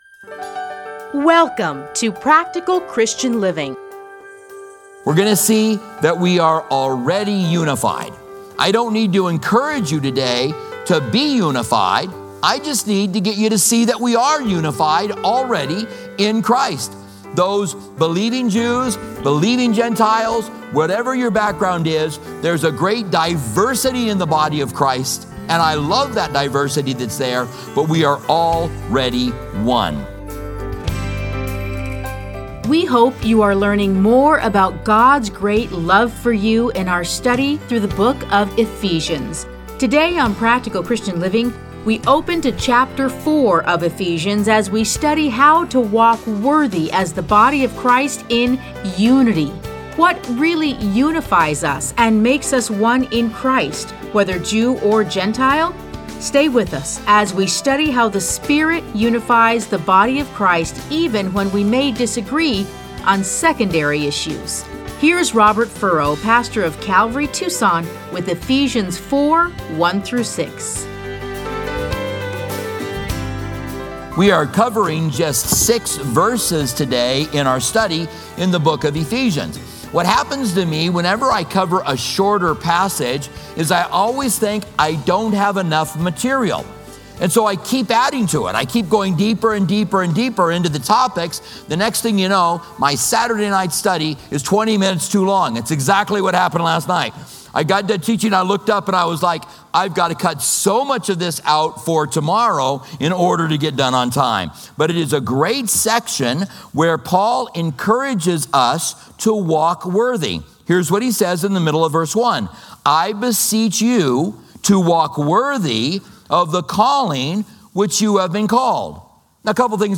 Listen to a teaching from Ephesians 4:1-6.